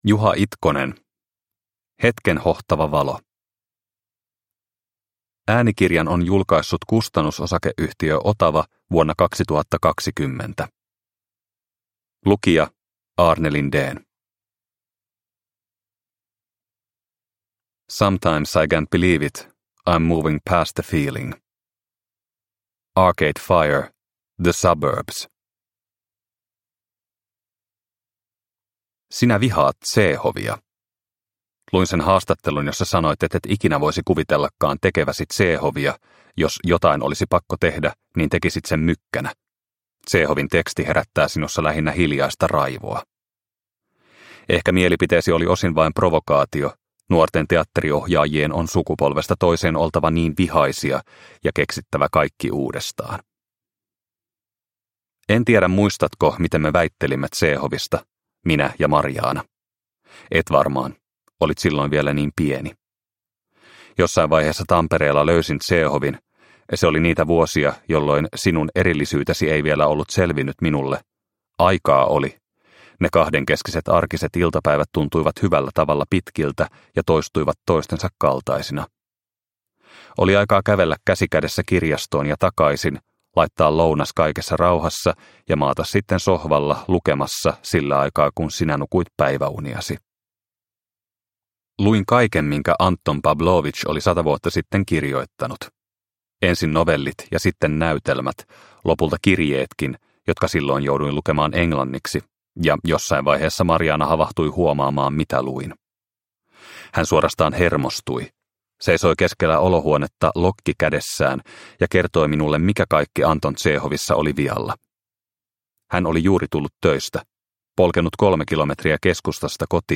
Hetken hohtava valo – Ljudbok – Laddas ner